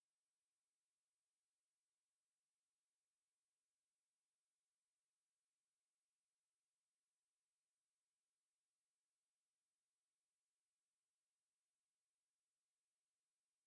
Hierbei handelt es sich um eine sog. „Silent Subliminal“-Aufnahme, eine Aufnahme in einem Frequenz-Bereich, welches für das Bewusstsein nicht hörbar ist jedoch für das Unterbewusstsein.
R1003-Suggestionsdusche-Rauchentwoehnung-Silent-Subliminal-Hoerprobe.mp3